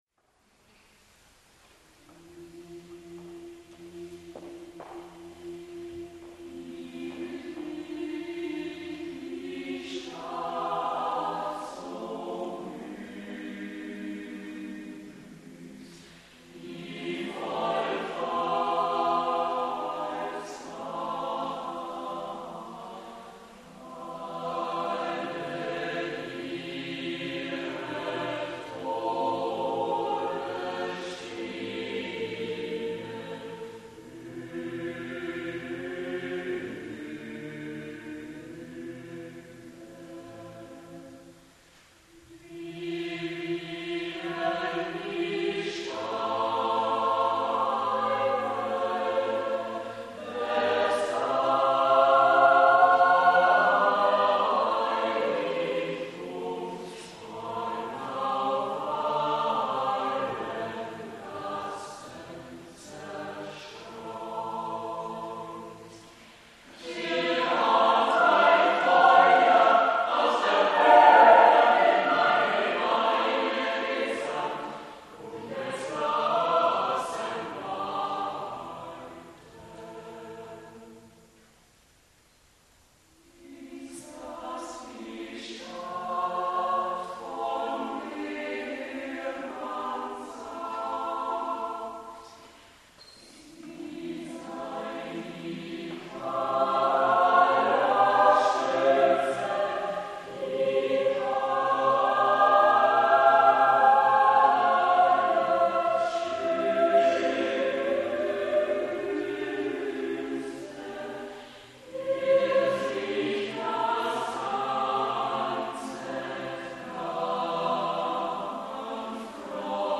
Gesungen von meinem früheren Chor (2,3 MB).